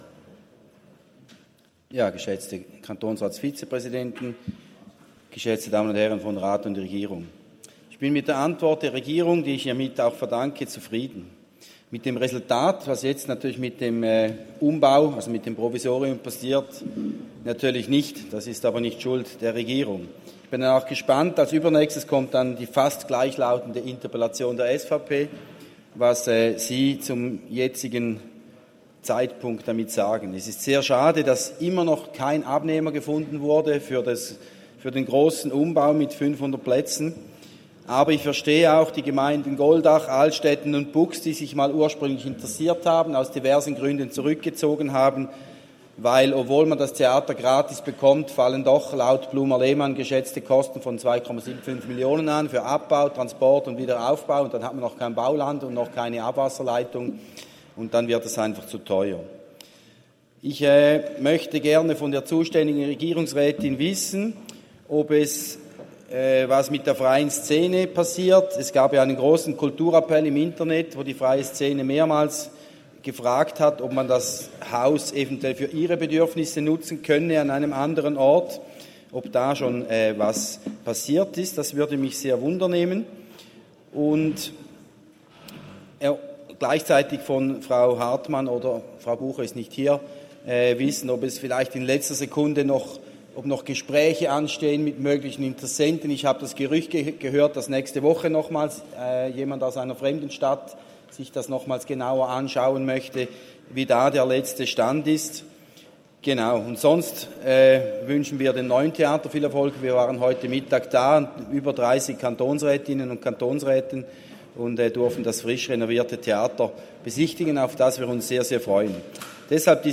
Session des Kantonsrates vom 18. bis 20. September 2023, Herbstsession
20.9.2023Wortmeldung